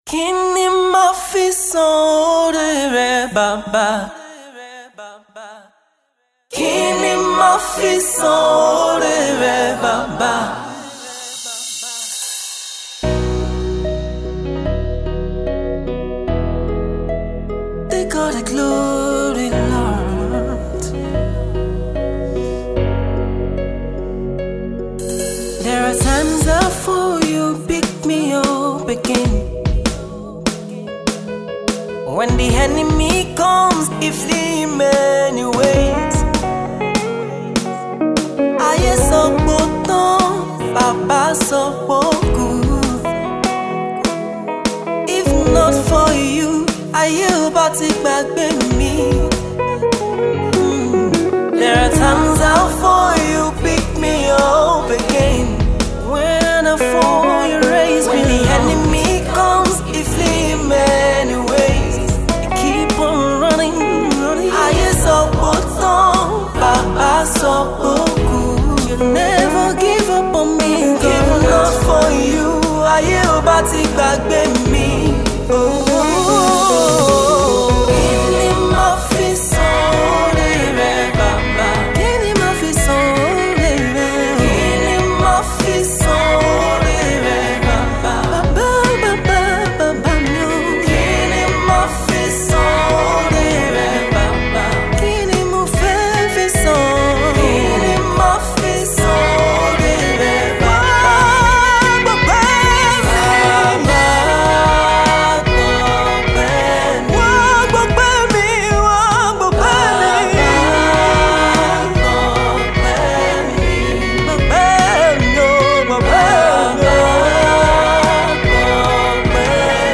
live recording editions